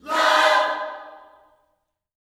LOVECHORD5.wav